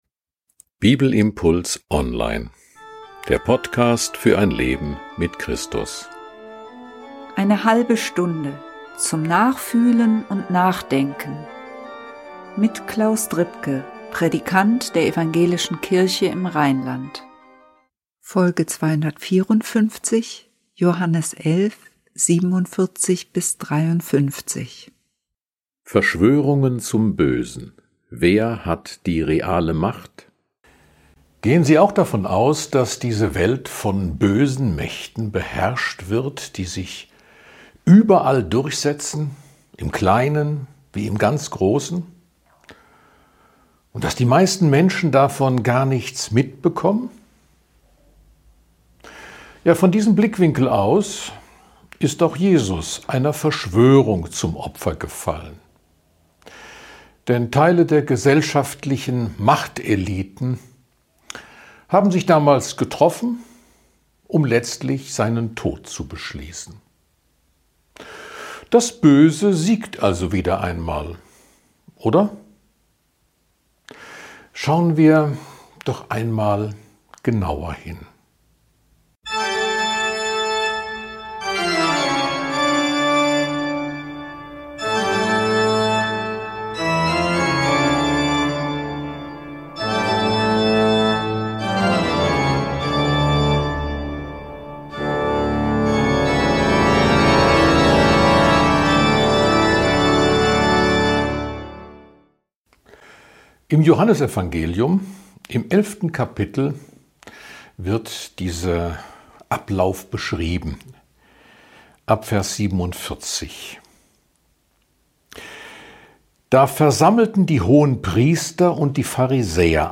Ein Bibelimpuls zu Johannes 11, 47-53.